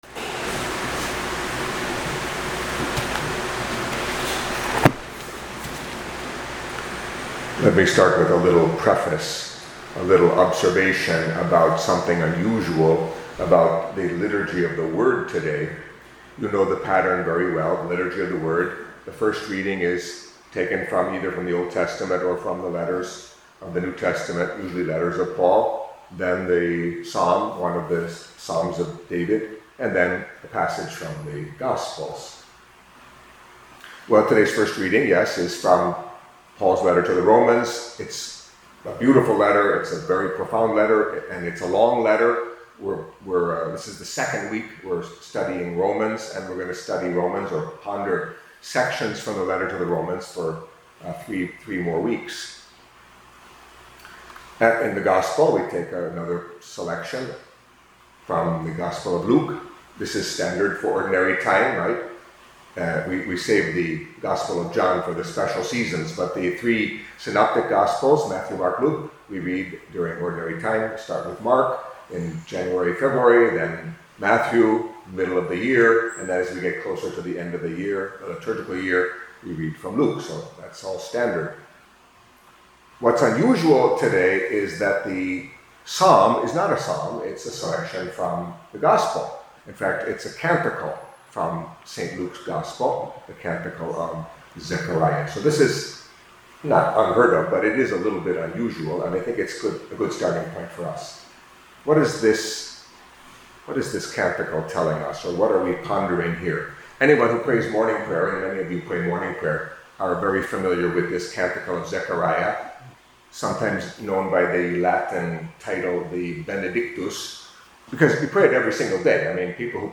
Catholic Mass homily for Monday of the Twenty-Ninth Week in Ordinary Time